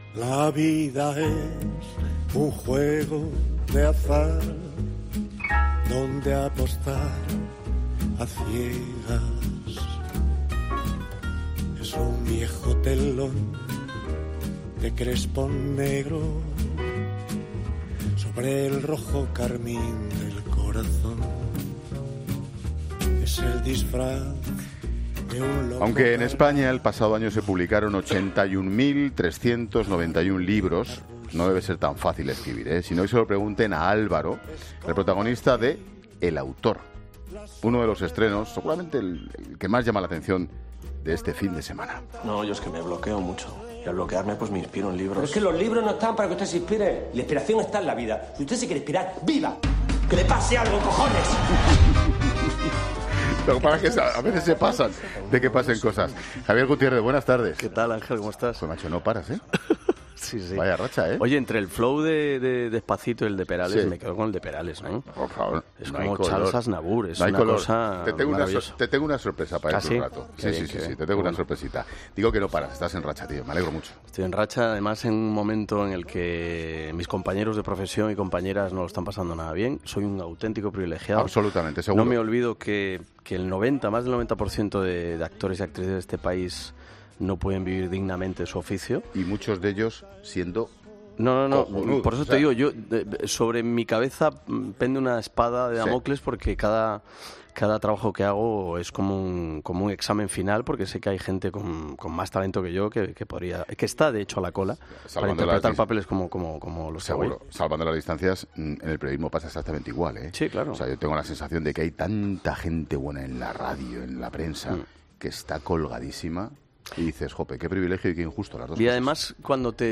El actor Javier Gutiérrez, protagonista de 'El autor', en 'La Tarde'
ESCUCHA LA ENTREVISTA COMPLETA | Javier Gutiérrez, en 'La Tarde' Gutiérrez cuenta que es una película para "paladear los silencios", y que no lo costó trabajo meterse en el personaje.